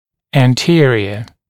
[æn’tɪərɪə][эн’тиэриэ]передний; относящийся к передней группе зубов